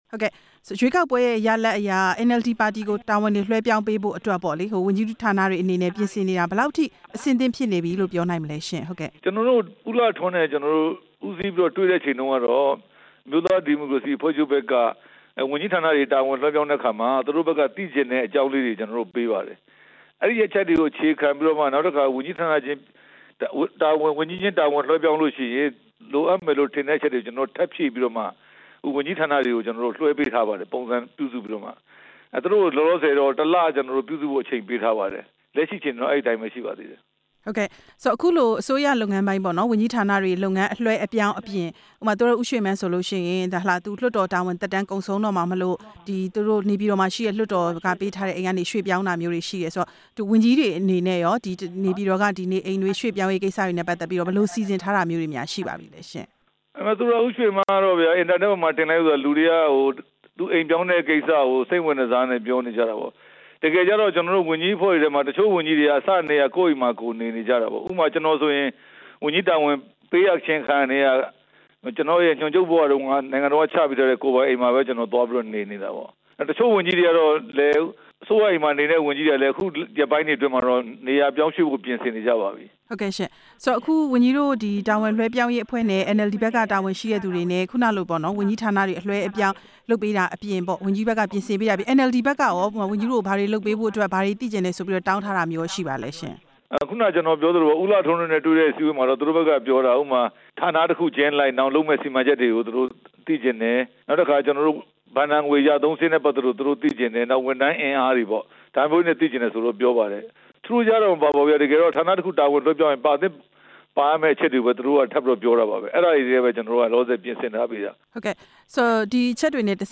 NLD ပါတီကို နိုင်ငံ့တာဝန်လွှဲပြောင်းရေး ဝန်ကြီး ဦးရဲထွဋ်နဲ့ မေးမြန်းချက်